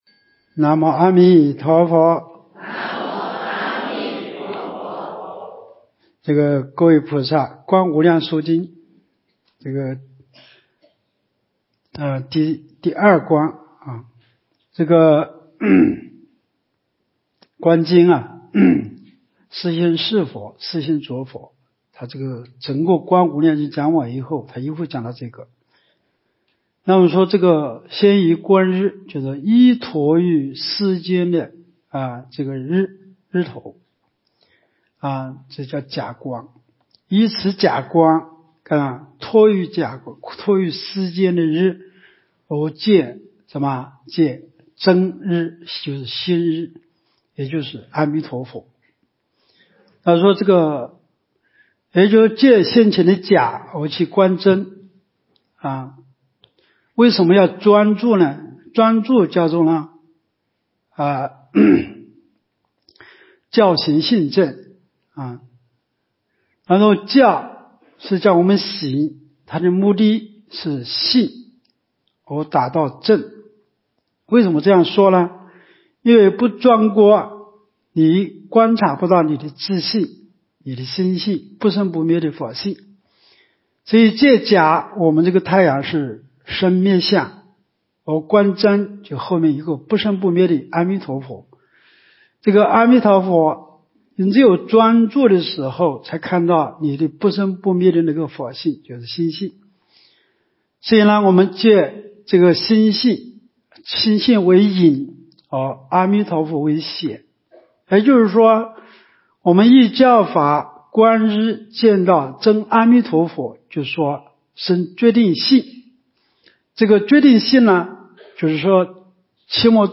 无量寿寺冬季极乐法会精进佛七开示（19）（观无量寿佛经）...